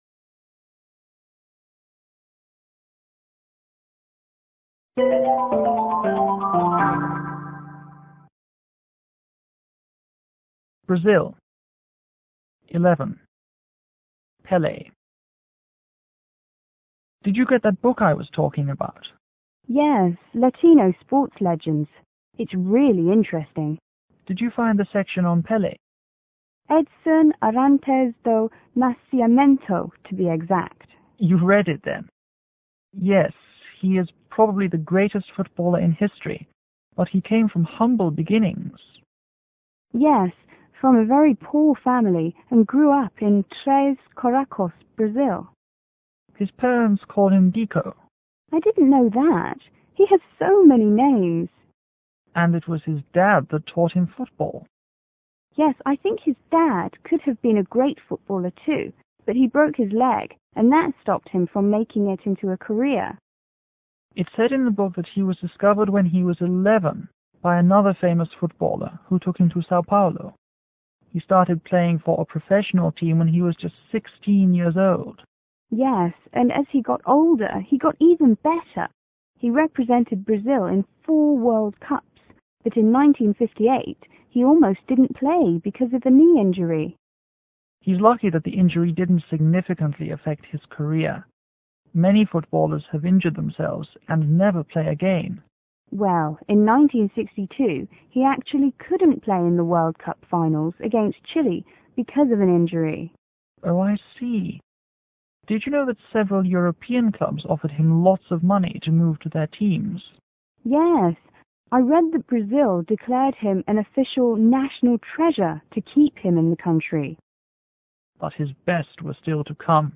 Fl : Friend I       F2 : Friend 2